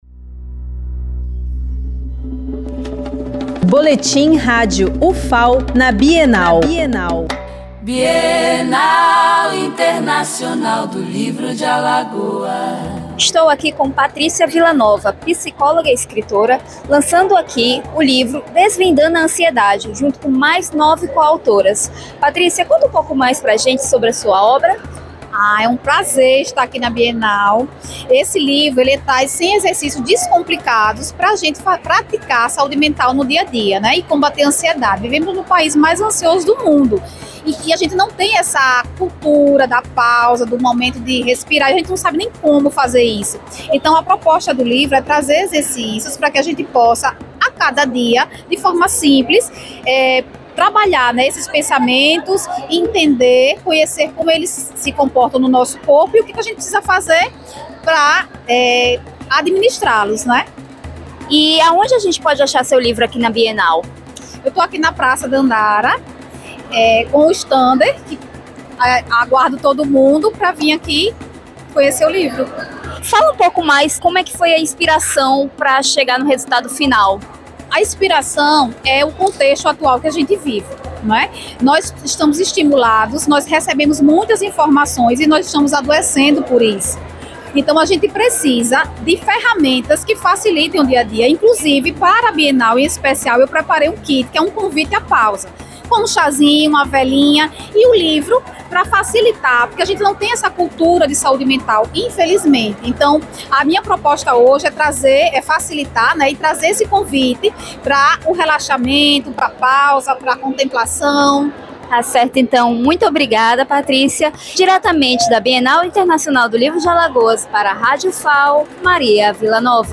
Flashes com informações da 11ª Bienal Internacional do Livro de Alagoas, realizada de 31 de outubro a 9 de novembro de 2025